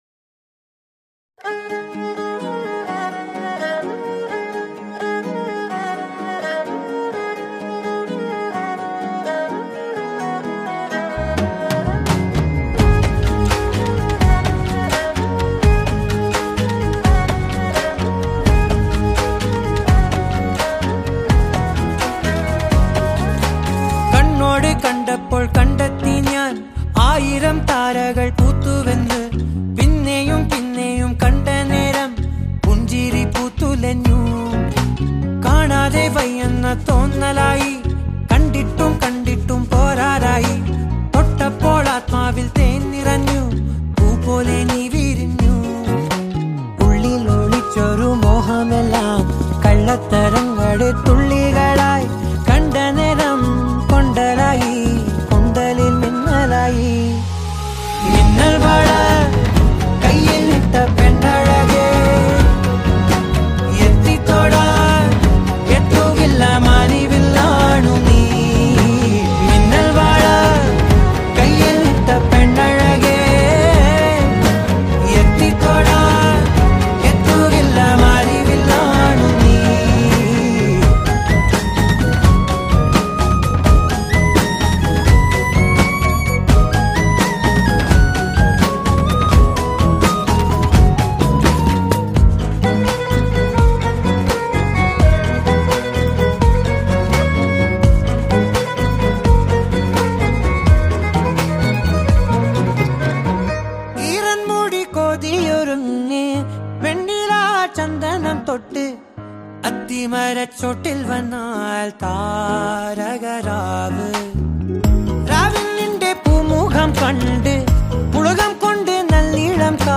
Malayalam Songs